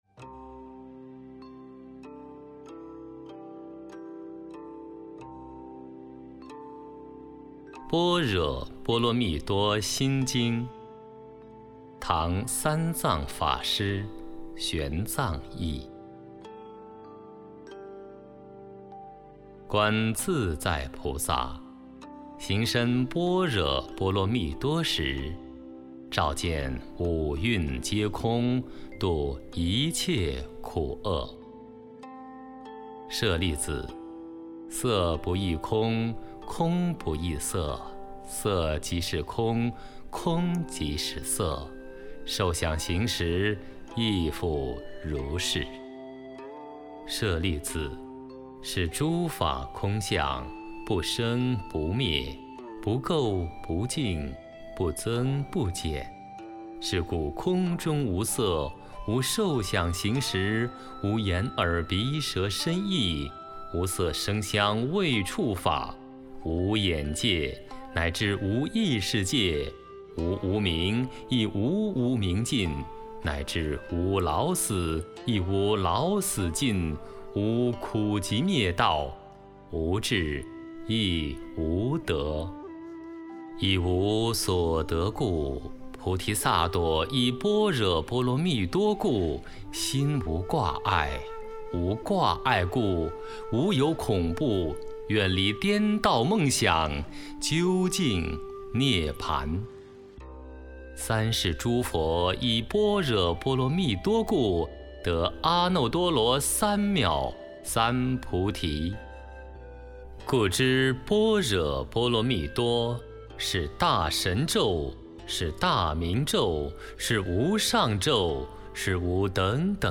心经读诵（本地音频） - 佛乐诵读
站内可直接播放的心经读诵版本，适合短时课诵、静坐前后与日常听诵。
xinjing-songdu.mp3